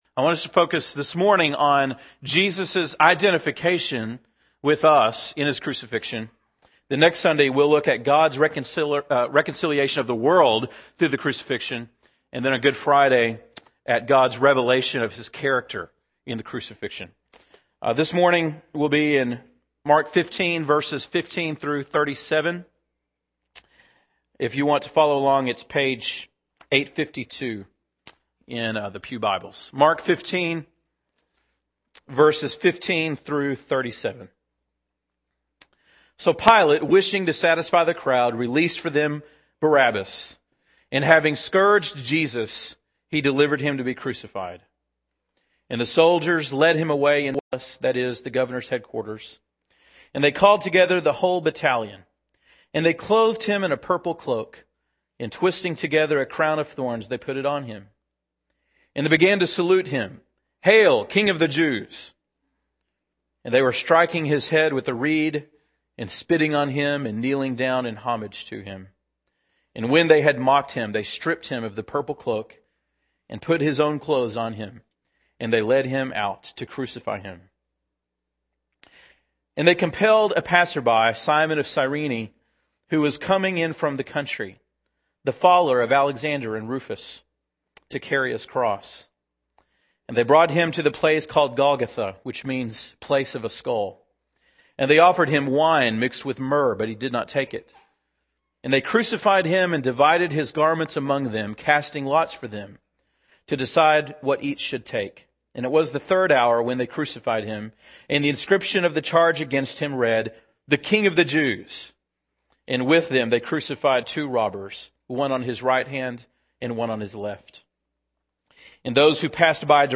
March 29, 2009 (Sunday Morning)